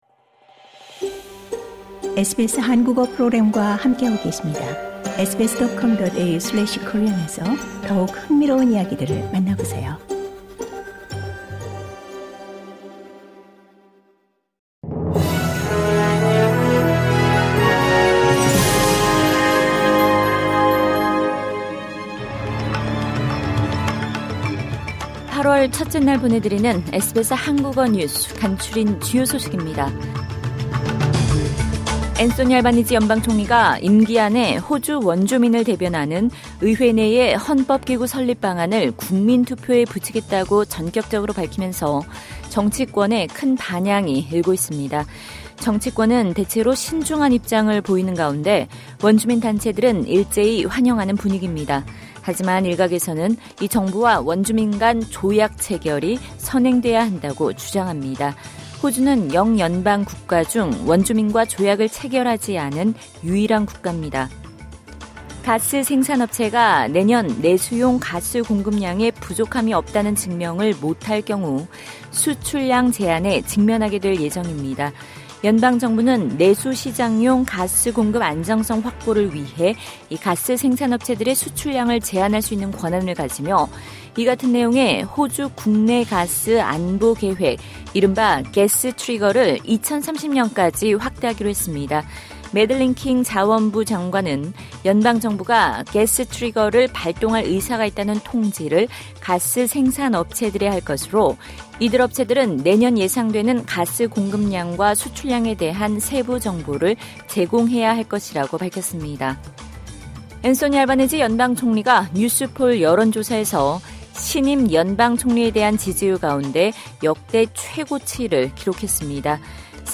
2022년 8월 1일 월요일 저녁 SBS 한국어 간추린 주요 뉴스입니다.